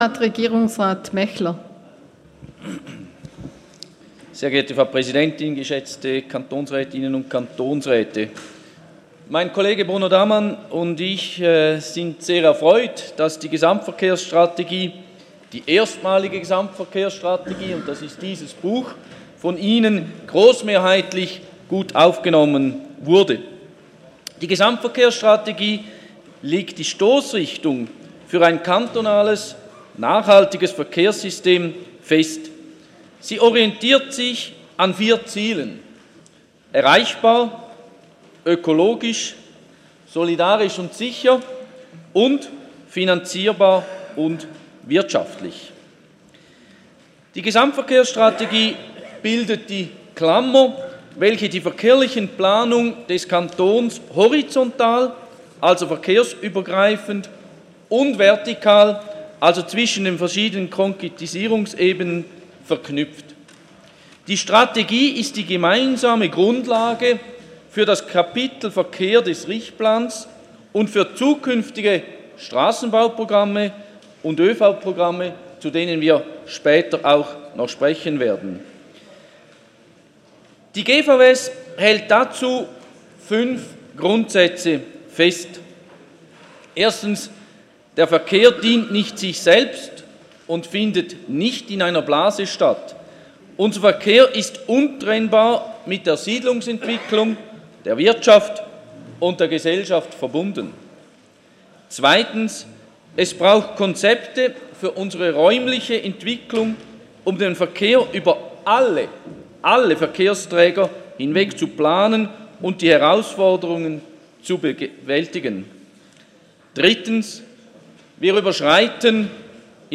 18.9.2018Wortmeldung
Session des Kantonsrates vom 17. bis 19. September 2018